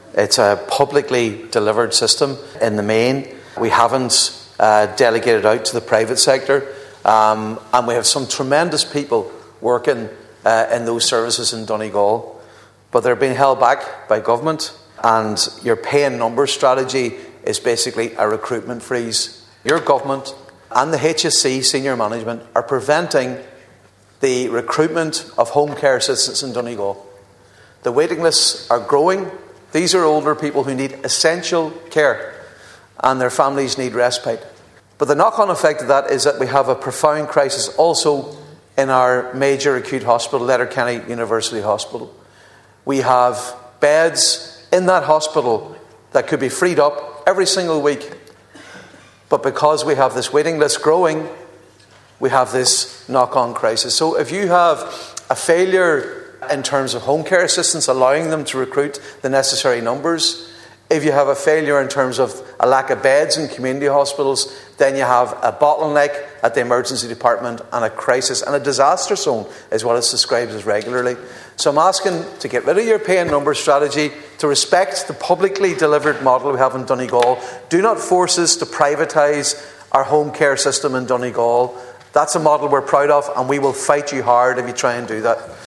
The issue was raised by Deputy Padraig Mac Lochlainn during a discussion on an Independent Ireland motion calling for a fairer funding model for home care and nursing homes.
Deputy MacLochlainn told TDs there is a tradition of public care in Donegal of which people are very proud, and if the government puts that at risk, it’ll have a fight on its hands………